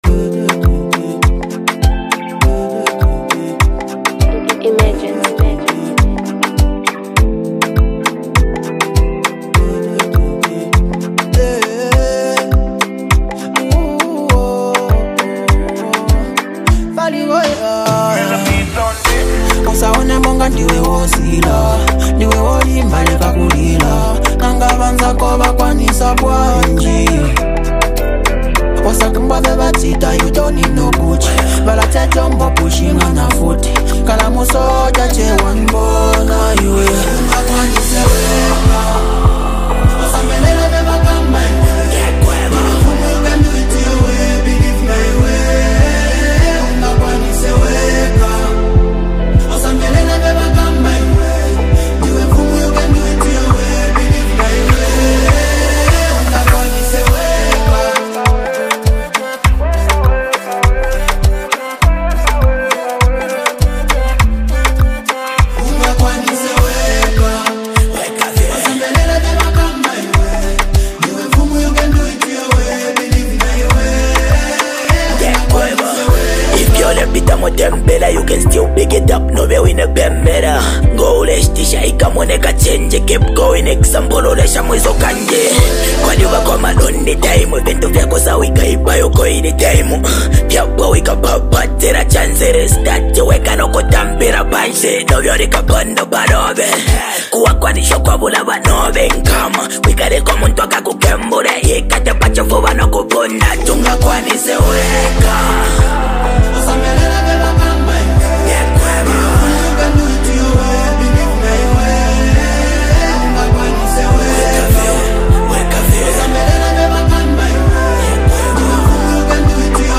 creating a track that's vibrant and captivating.